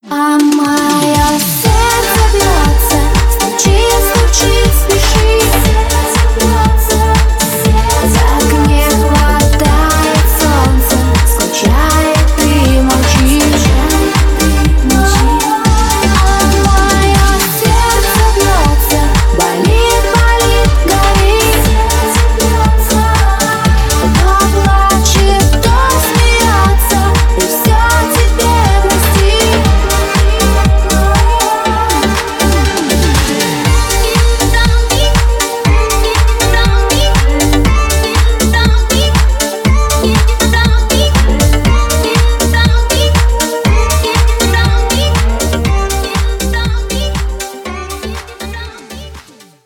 • Качество: 320, Stereo
женский вокал
dance